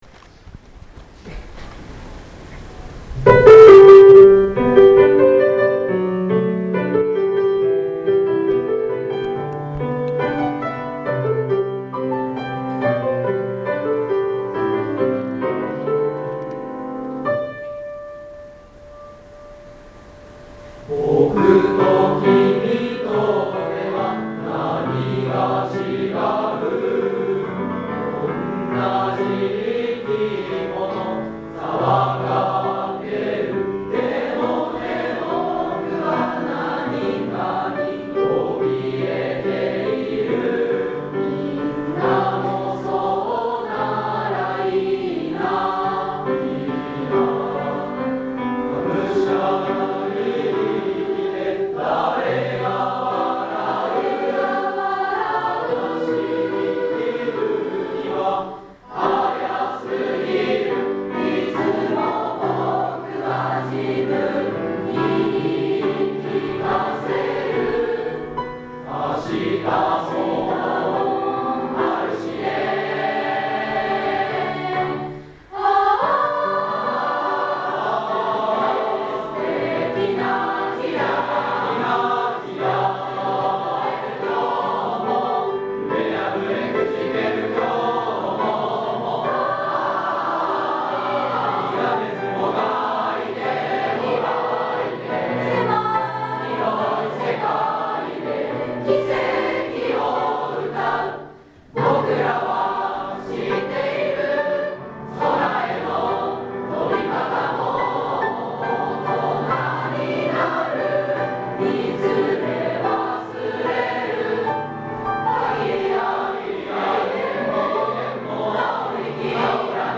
本日、第78回卒業式を挙行いたしました。
↓↓↓クリックして卒業生の合唱を聞いてください。卒業生の歌「僕のこと」